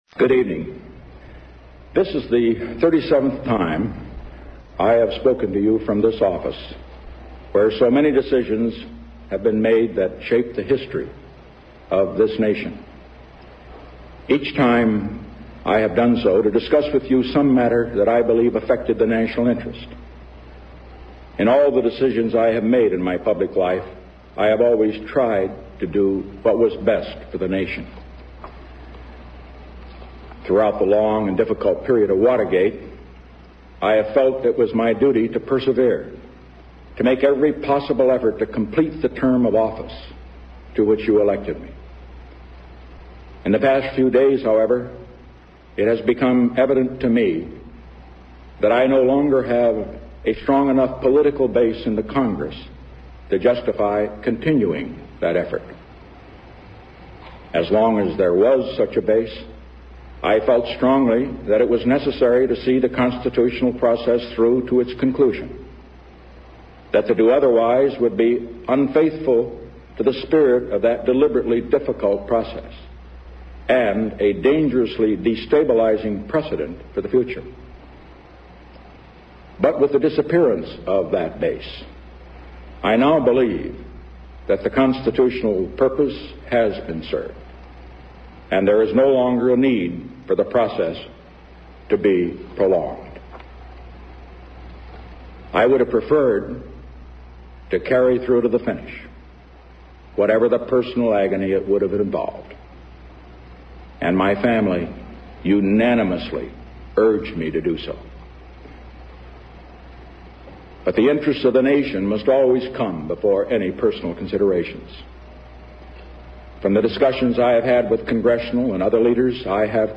Richard M. Nixon Resignation Address delivered 8 August 1974